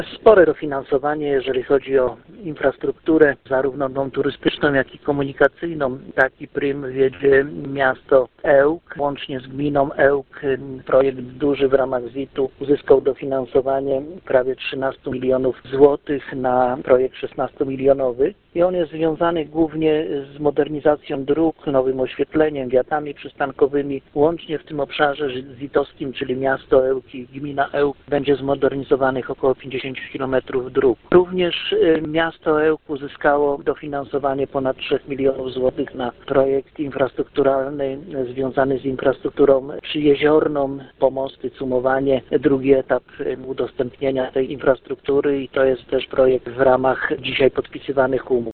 Jak poinformował Radio 5 Gustaw Marek Brzezin, marszałek Województwa Warmińsko-Mazurskiego duże inwestycje realizowane będą między innymi we wspomnianych mieście i Gminie Ełk, w Węgorzewie oraz Orzyszu.
marszałek1.mp3